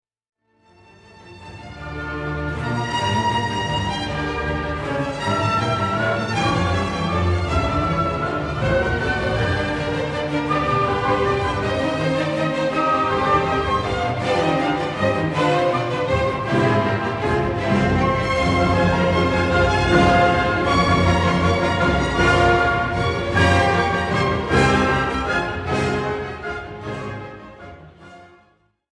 The first theme, so confident in the beginning, has turned into an incredibly dramatic character, threatening and also a little panicking.